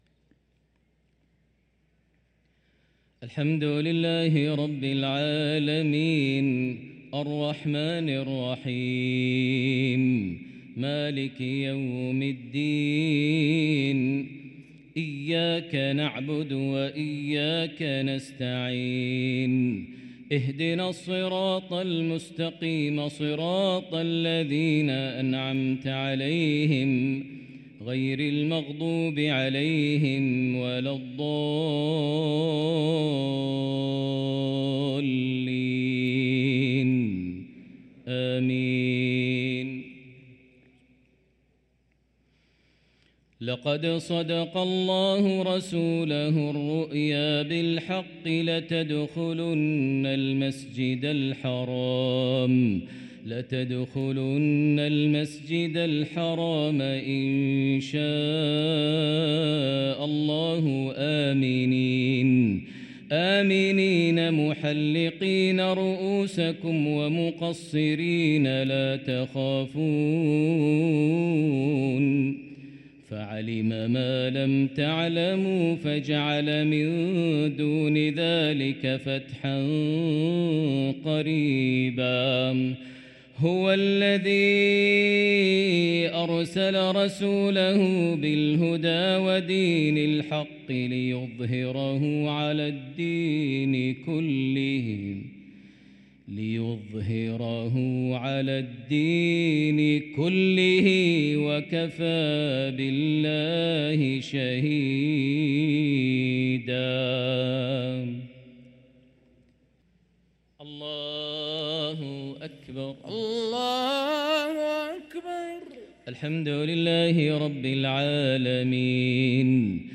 صلاة المغرب للقارئ ماهر المعيقلي 22 ربيع الأول 1445 هـ
تِلَاوَات الْحَرَمَيْن .